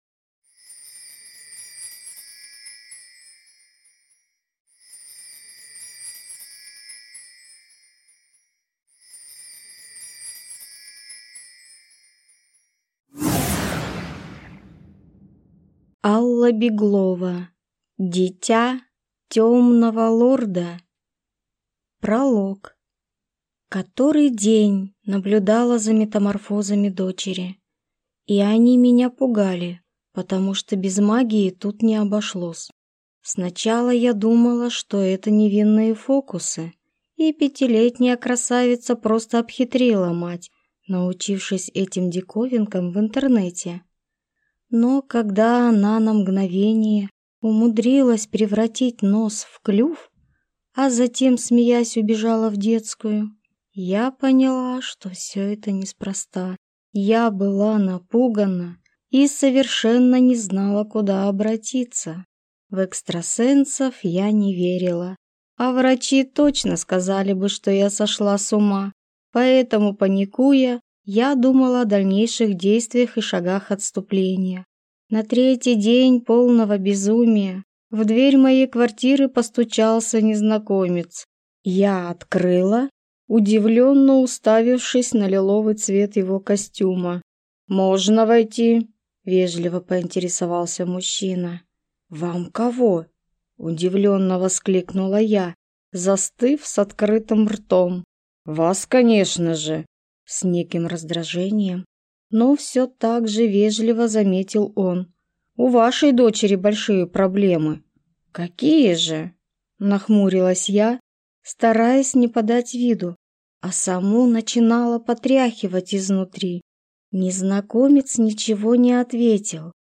Аудиокнига Дитя Тёмного Лорда | Библиотека аудиокниг